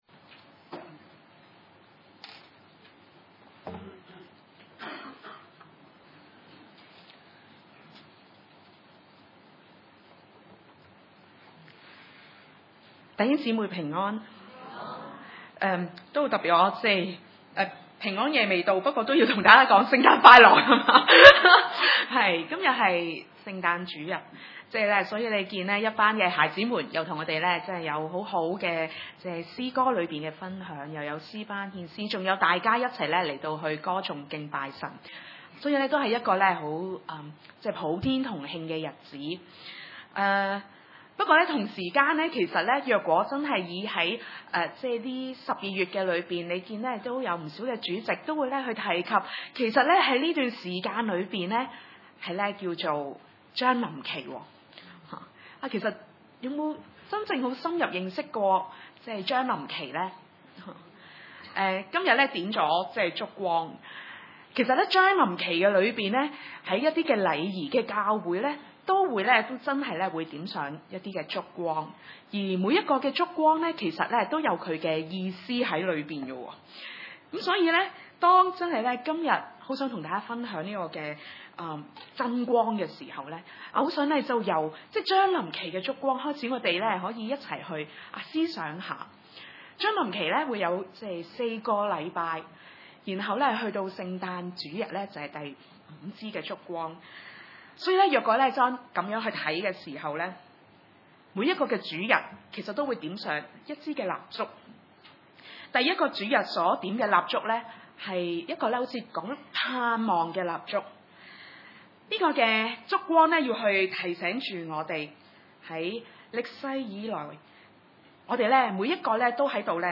14-18 崇拜類別: 主日午堂崇拜 14 道成了肉身，住在我們中間，充充滿滿地有恩典有真理。